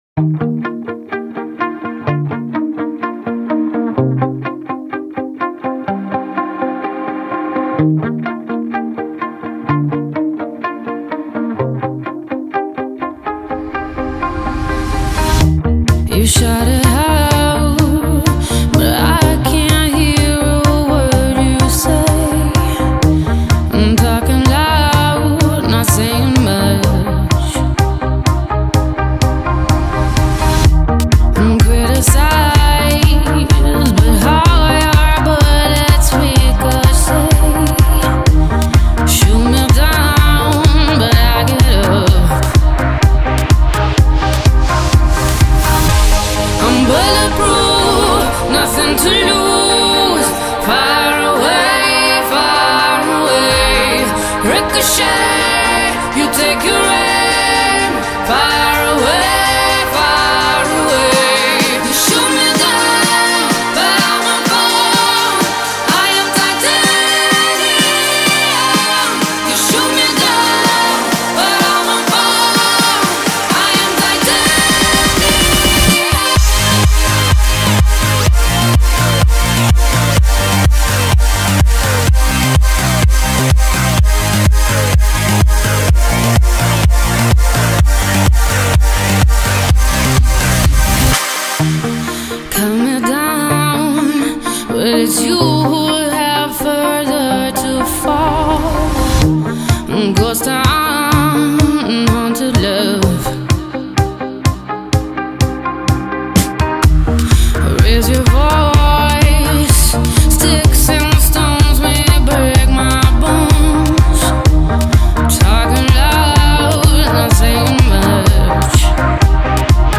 dance-pop anthems
With its soaring vocals, pounding beats
raspy yet powerfully elastic
Then comes the explosive chorus